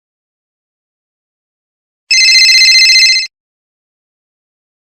Phone Ring
Phone Ring is a free sfx sound effect available for download in MP3 format.
017_phone_ring.mp3